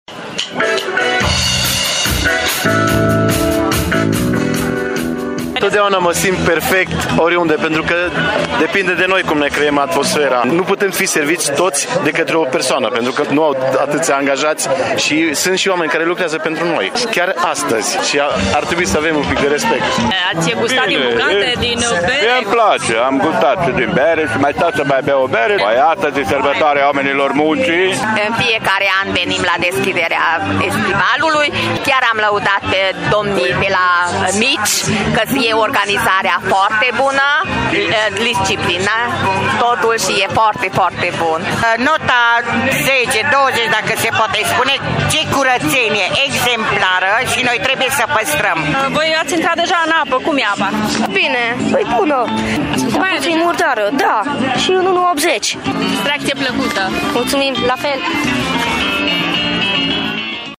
S-a stat mult la cozi pentru mititei, cârnăciori, gulaș și bere însă oamenii nu au fost nemulțumiți, ci, dimpotrivă, chiar sunt recunoscători: